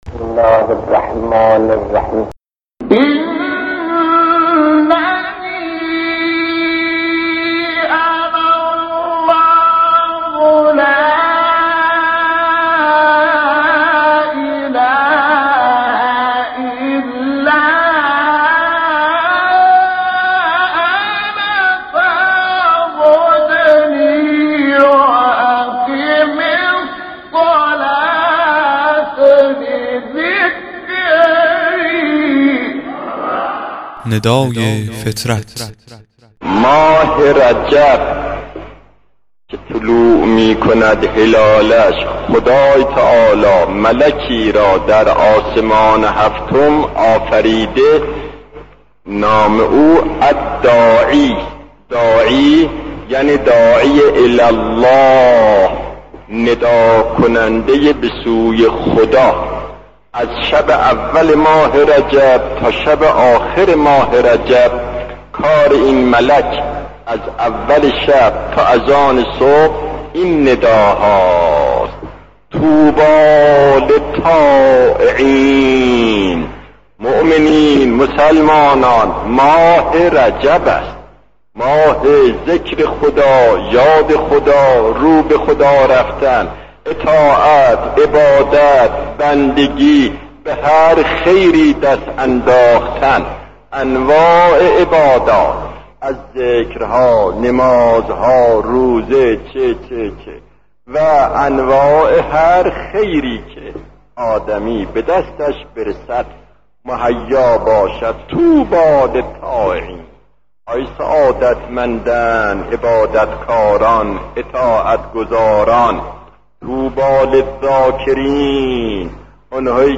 صوتی کوتاه از آیت الله دستغیب(ره) درباره فرشته داعی
مواعظ گوناگون و مطالب و نکاتی که در قالب صوت هستند و مختصر و مفید می‌باشند و پند و اندرز می‌دهند، در این بخش مطرح می‌شود.